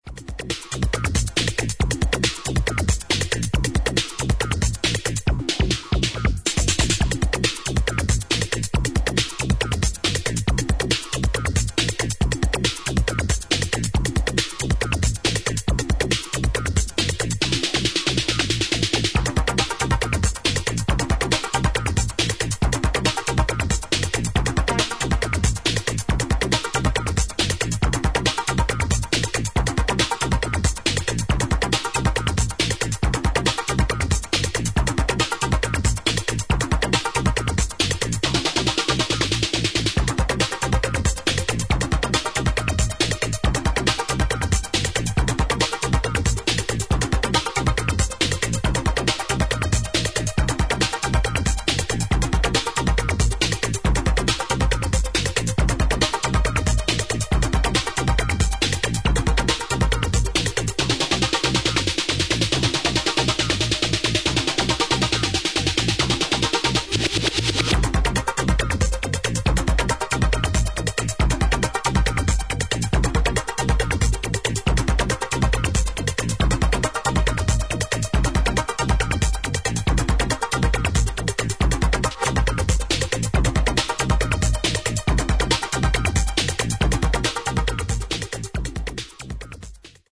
今聴くとレトロ・フューチャーな感触を覚える作品です。